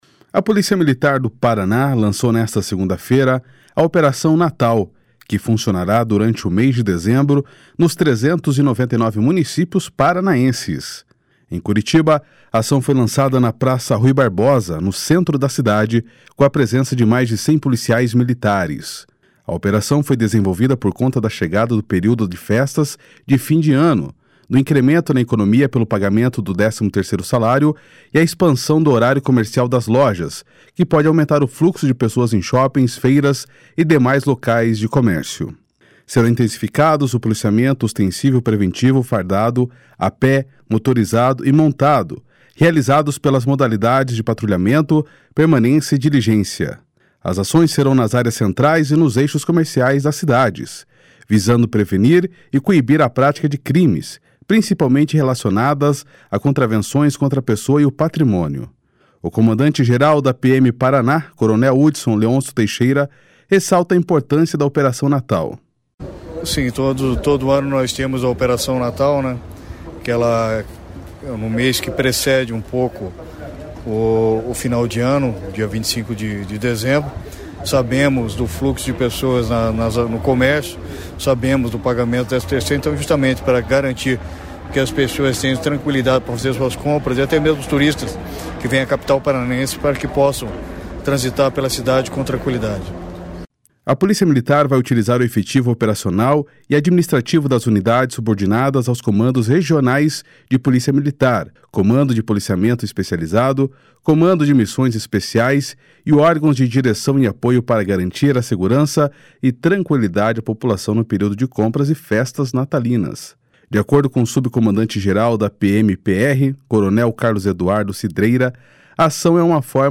O comandante-geral da PMPR, coronel Hudson Leôncio Teixeira, ressalta a importância da Operação Natal.//SONORA CORONEL HUDSON//
De acordo com o subcomandante-geral da PMPR, coronel Carlos Eduardo Cidreira, a ação é uma forma a garantir que as pessoas, os turistas e os comerciantes, tenham mais tranquilidade na hora de transitar e fazerem suas compras.//SONORA CORONEL CARLOS CIDREIRA//